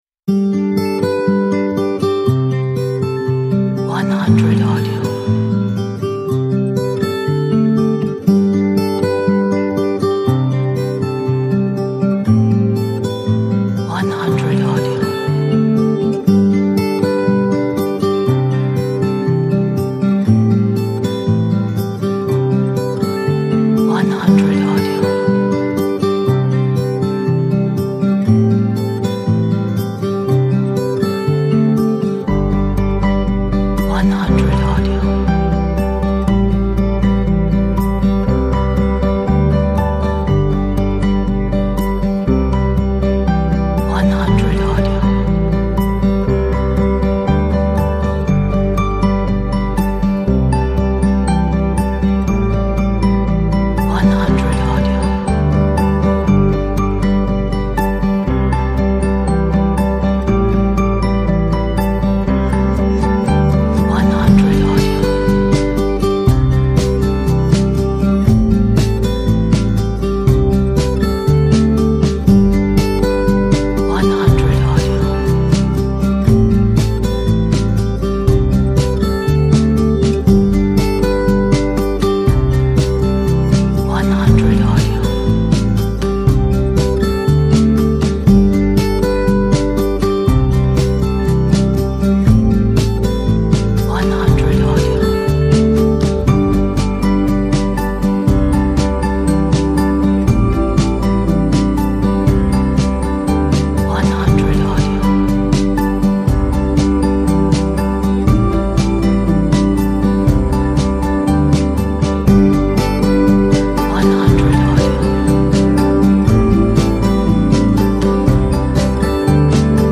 煽情 乐观 不插电 背景